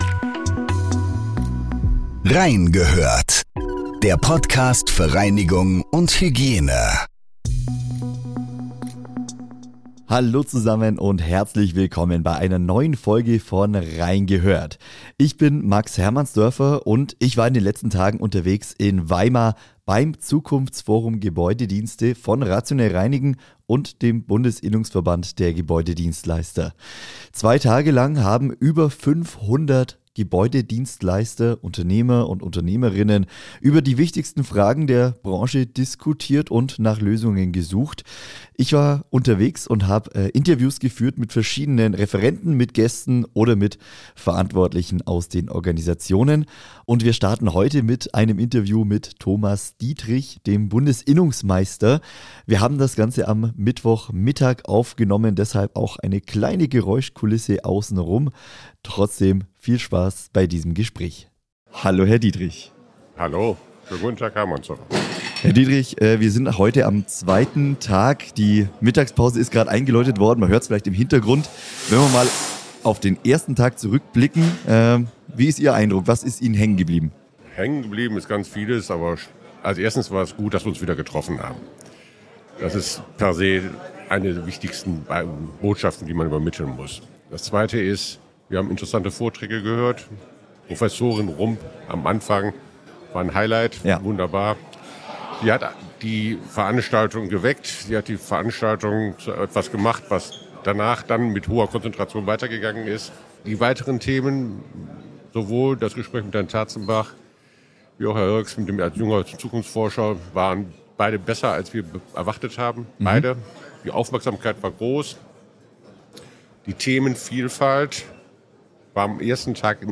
Mehr als 500 Teilnehmer haben am 05. und 06.11. beim Zukunftsforum